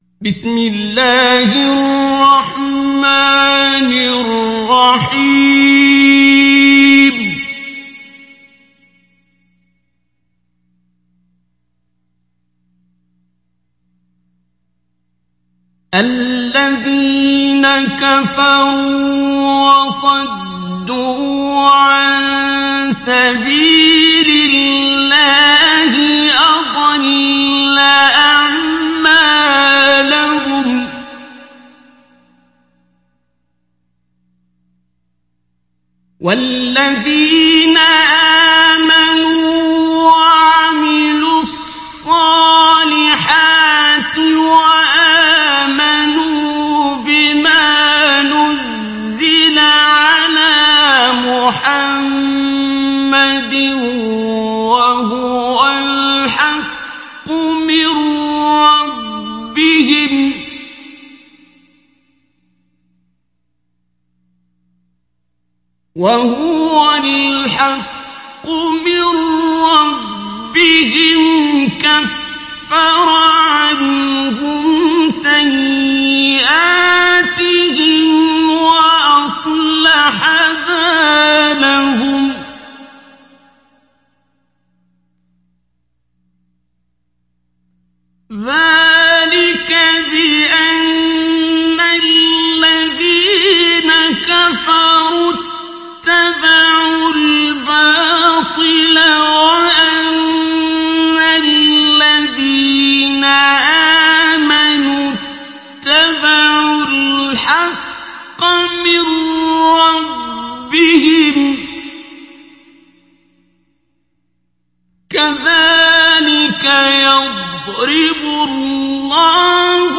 Surat Muhammad Download mp3 Abdul Basit Abd Alsamad Mujawwad Riwayat Hafs dari Asim, Download Quran dan mendengarkan mp3 tautan langsung penuh